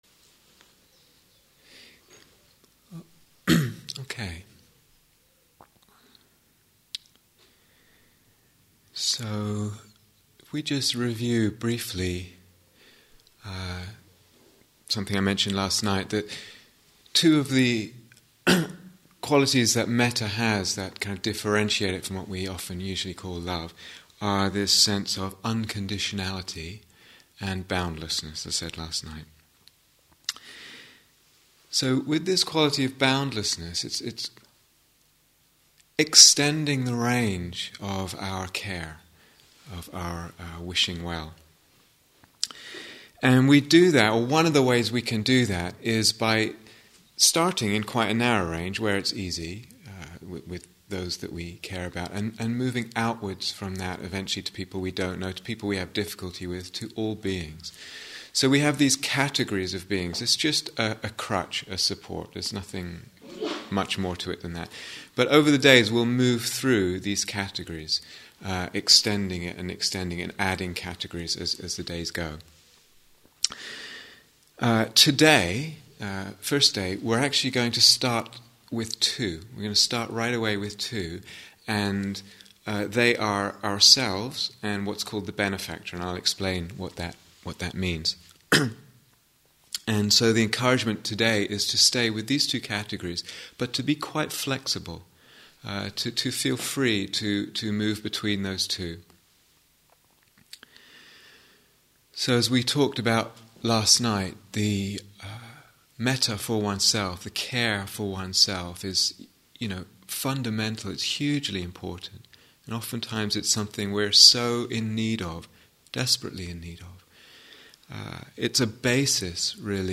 Mettā Instructions and Guided Meditation 1
Here is the full retreat on Dharma Seed Download 0:00:00 53:22 Date 7th August 2010 Retreat/Series The Lovingkindness (Mettā) Retreat